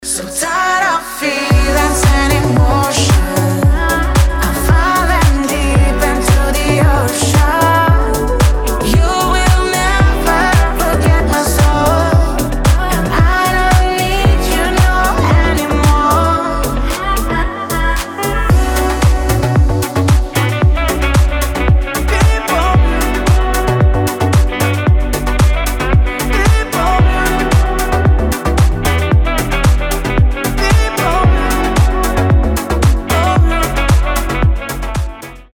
• Качество: 320, Stereo
deep house
Саксофон
приятный женский голос